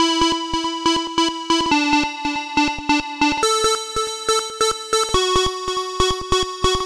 Tag: 14 bpm Dubstep Loops Synth Loops 1.15 MB wav Key : Unknown